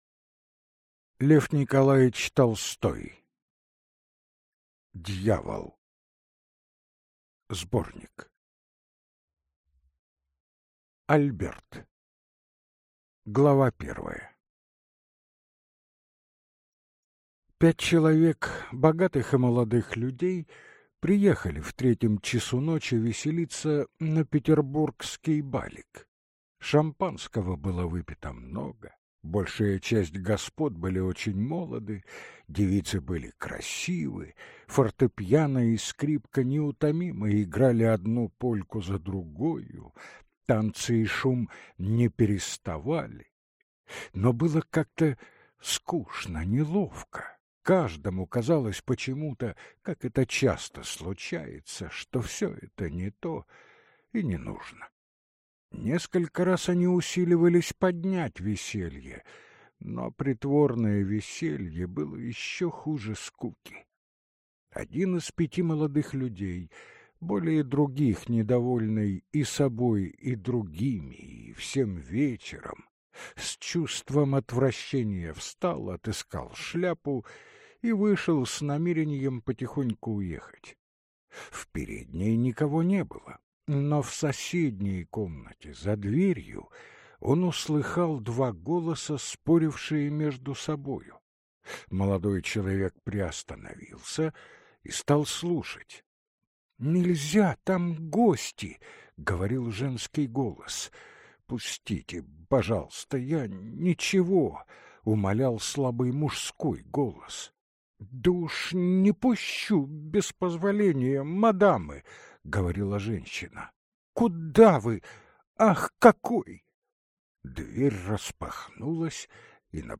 Аудиокнига Дьявол | Библиотека аудиокниг